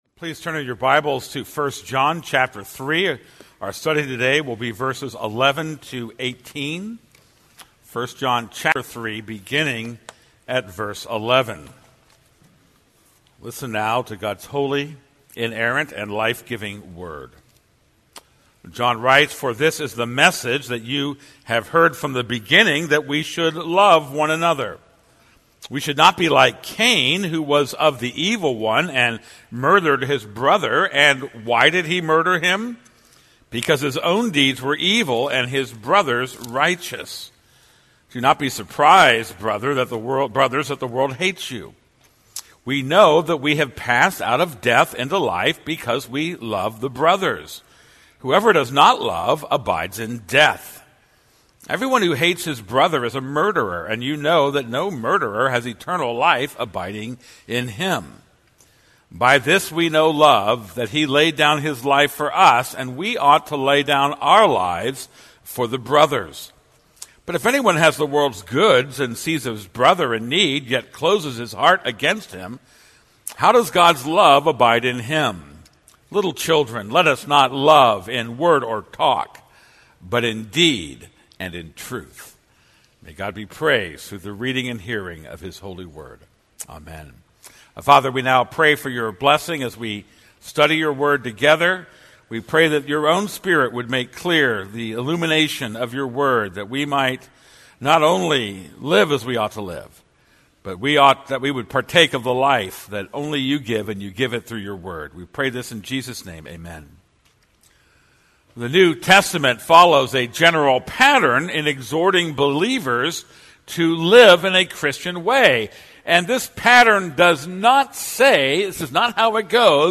This is a sermon on 1 John 3:11-18.